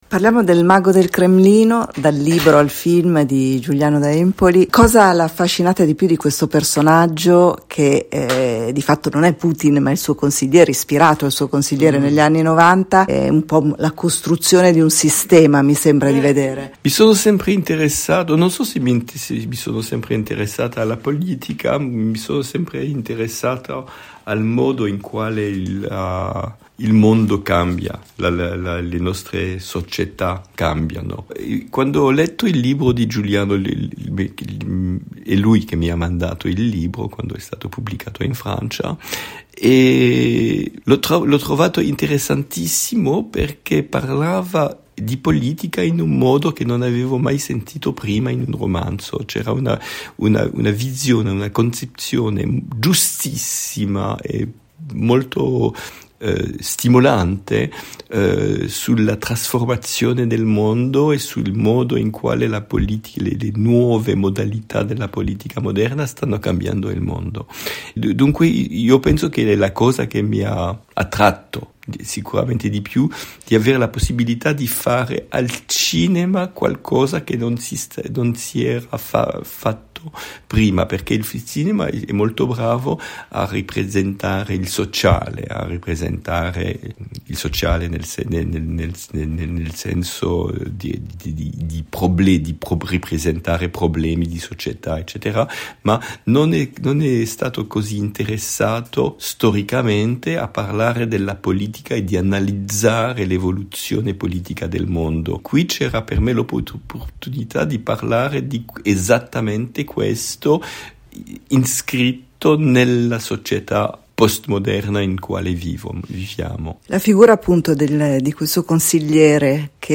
In italiano perfetto, il regista francese Olivier Assayas racconta di essere stato affascinato dall’università di questa storia, da come la manipolazione della realtà e la costruzione dell’immagine siano un mezzo per la conquista del potere.